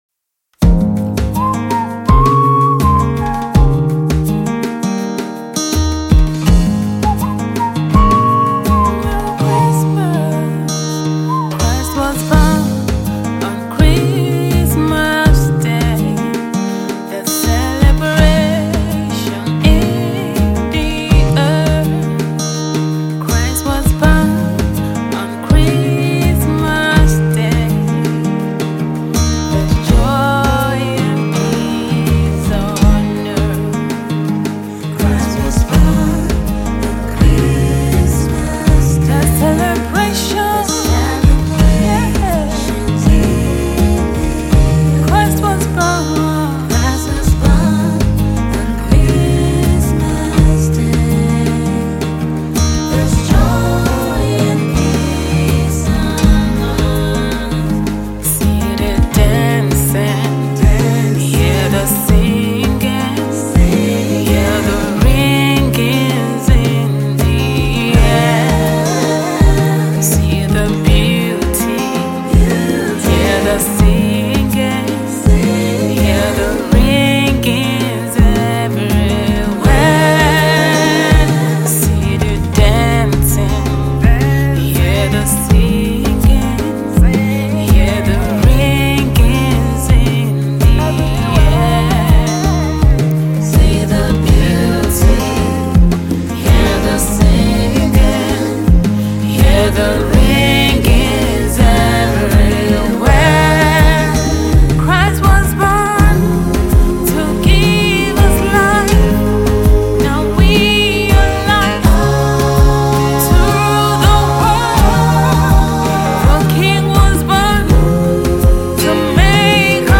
Christmas song